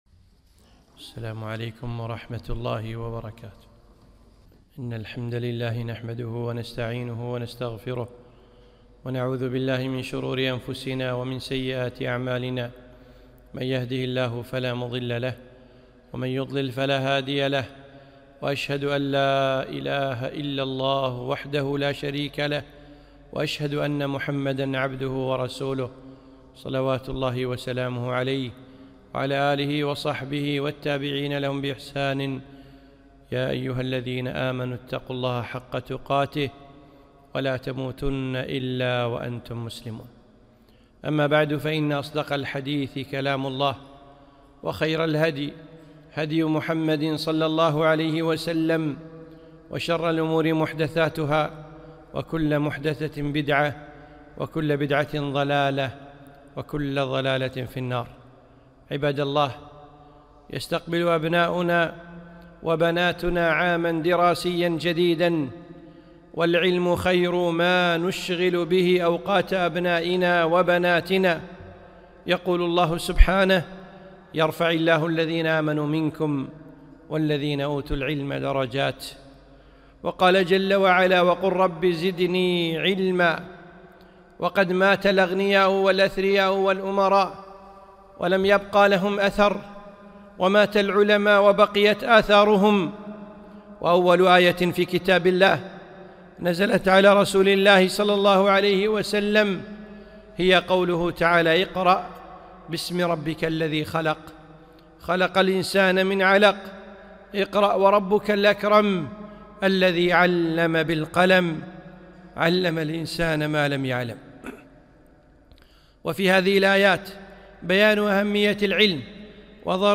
خطبة - أولادنا والعلم